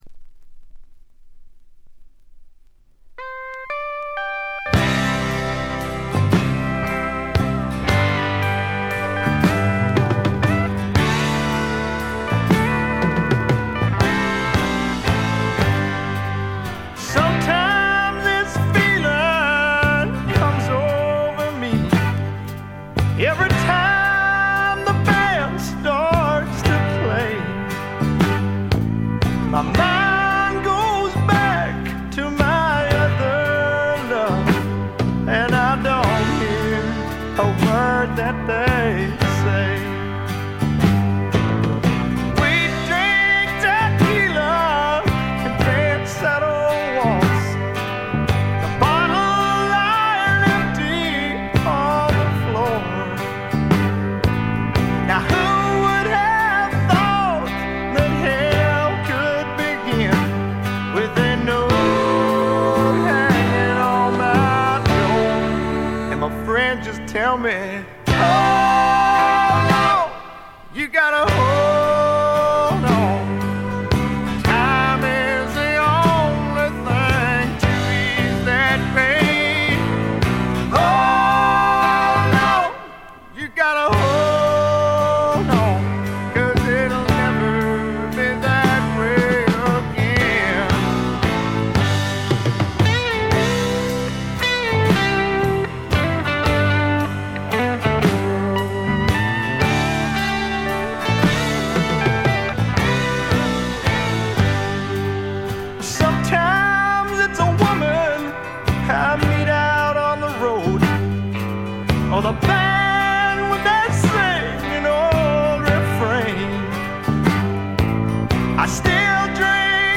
部分試聴ですがほとんどノイズ感無し。
70年代末期にあって、あっぱれなスワンプ魂（ザ・バンド魂）を見せてくれました。
試聴曲は現品からの取り込み音源です。